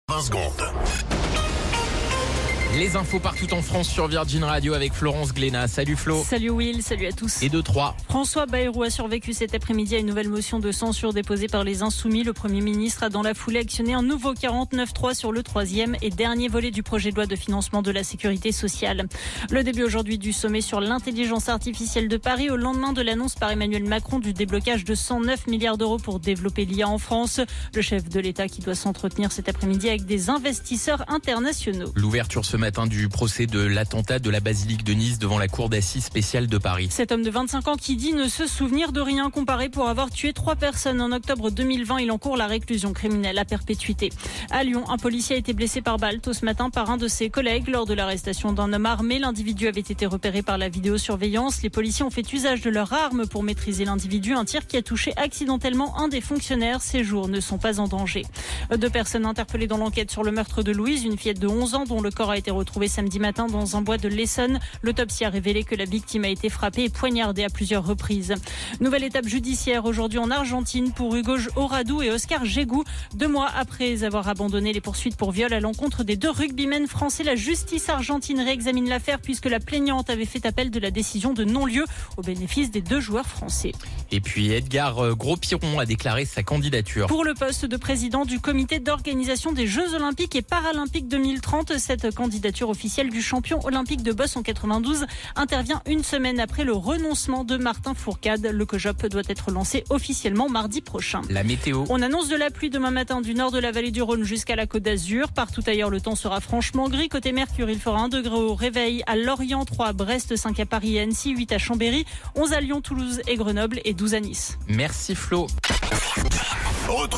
Flash Info National 10 Février 2025 Du 10/02/2025 à 17h10 .